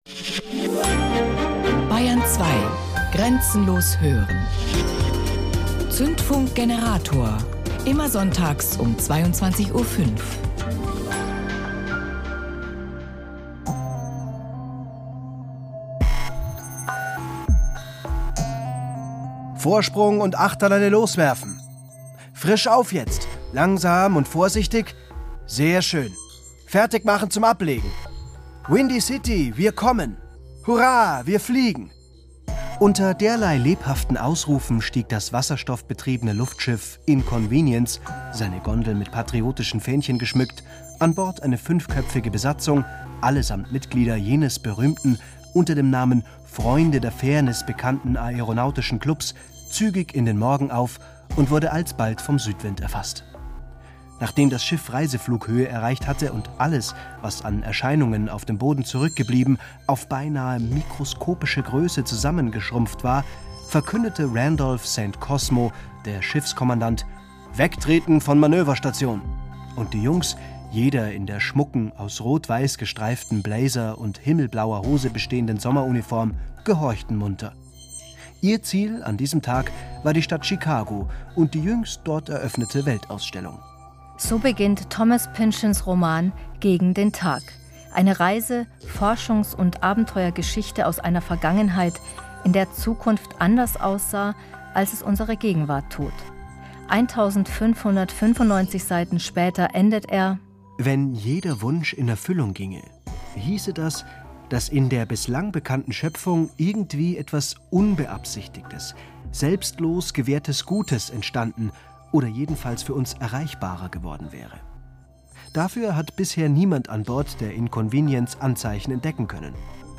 Radiointerview auf Bayern 2 zum Thema Steampunk
Bayern2_Interview.mp3